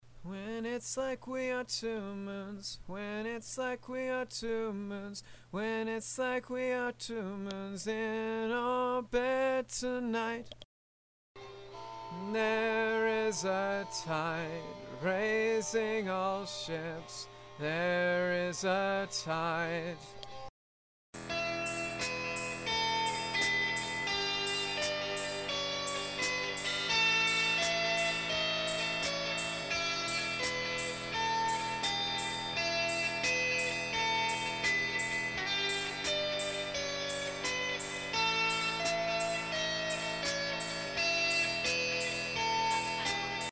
thirdsongvocalsandthirdpart.mp3